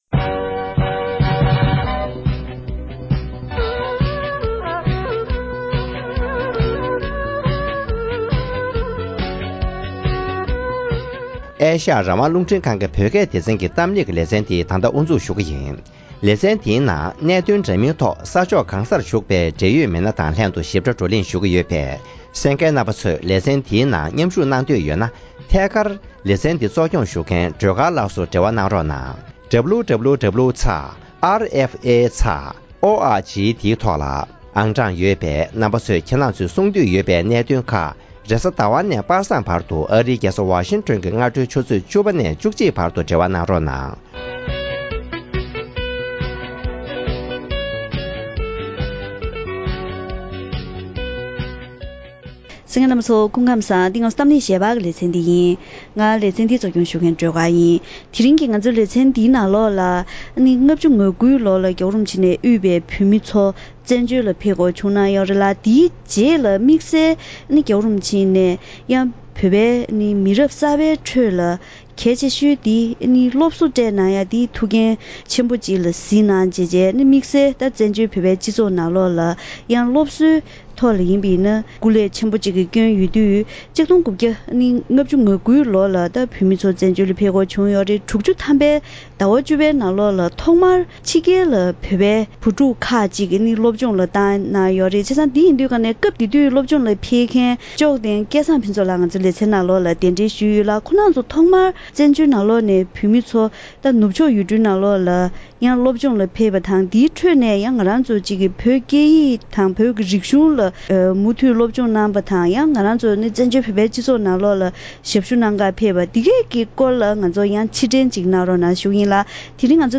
གཏམ་གླེང་